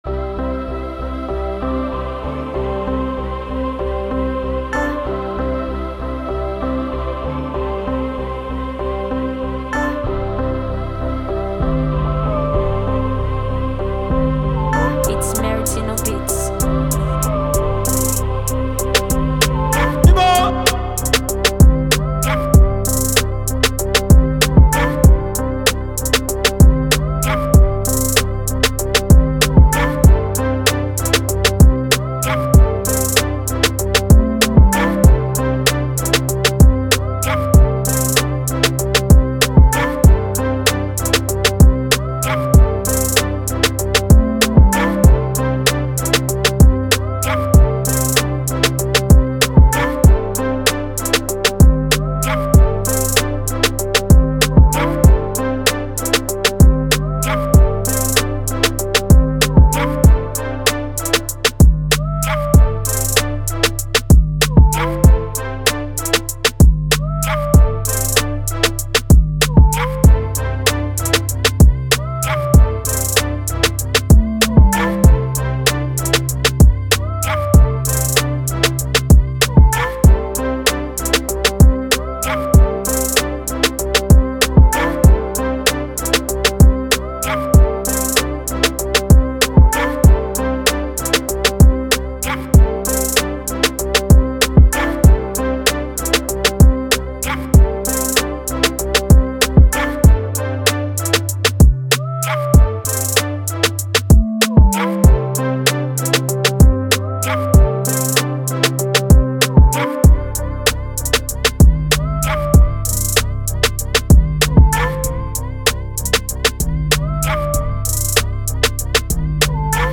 afrotrap instrumental
it’s a fire afro type rap beat for rap artists.